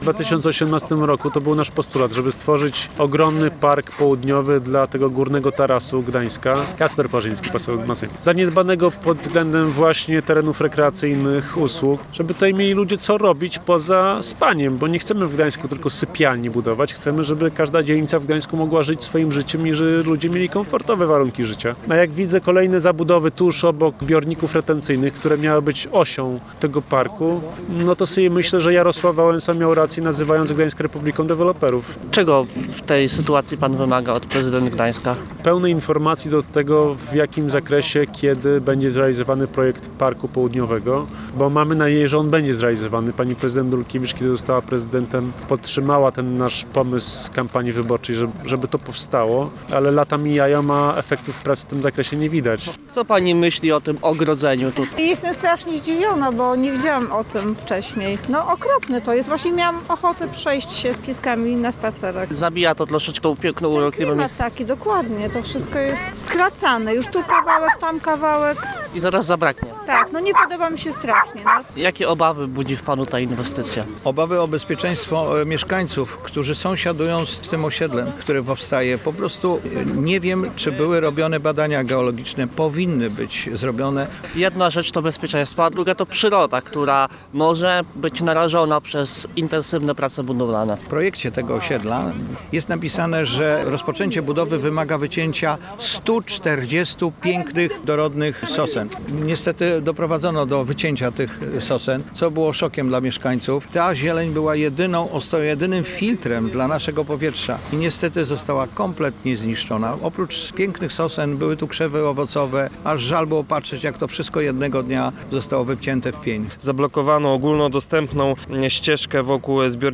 – Zablokowano ogólnodostępną ścieżkę wokół zbiornika retencyjnego. Mieszkańcy oddawali na nią głos w budżecie obywatelskim po to, żeby deweloper mógł tutaj zrealizować swoją budowę w bardzo bliskiej okolicy zbiornika. To jest nie do pomyślenia, ale takie są realia w Gdańsku. Zamiast Parku Południowego, zamiast zieleni są nowe osiedla deweloperskie – grzmi miejski radny PiS Przemysław Majewski.